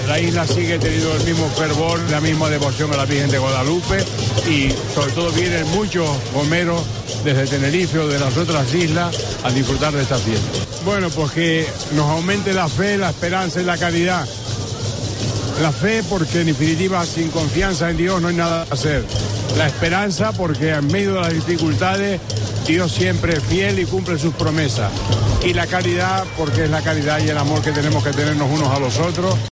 El obispo Bernardo Álvarez cuenta los sentimientos que se viven en torno a la Virgen de Guadalupe